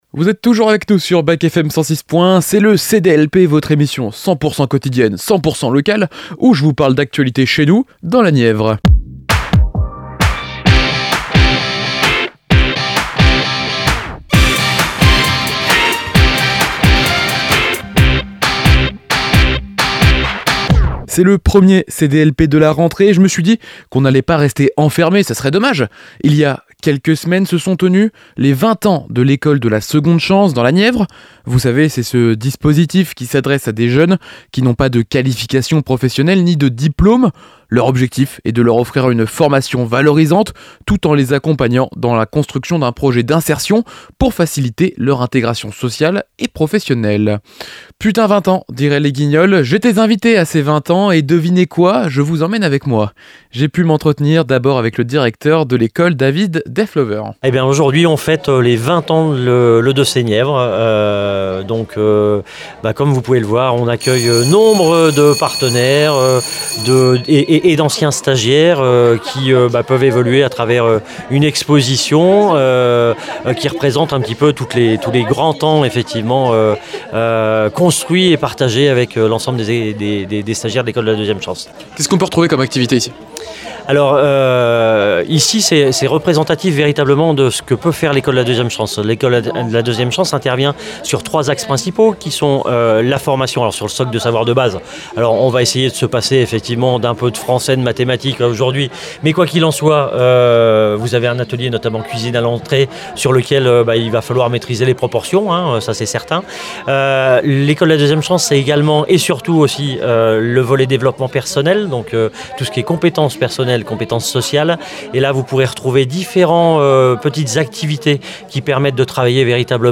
L’École de la 2ème Chance de la Nièvre vient de célébrer ses 20 ans dans ses nouveaux locaux situés à Marzy.